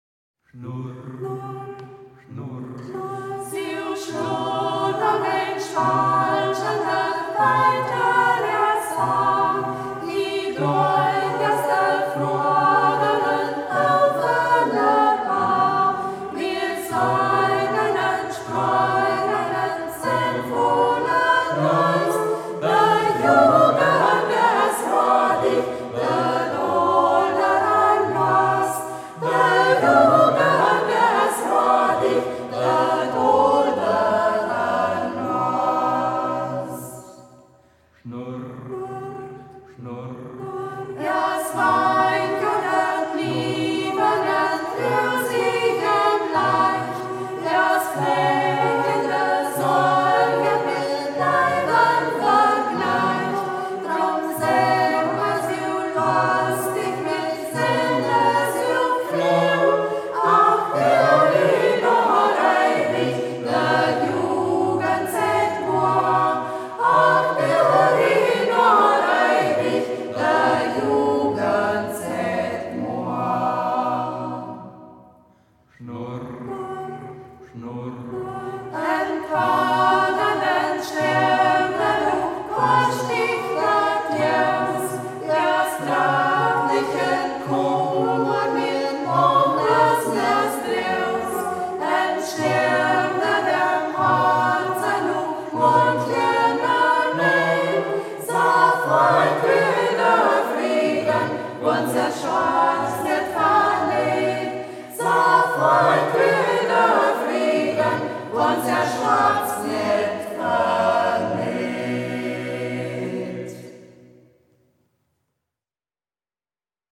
Ortsmundart: Braller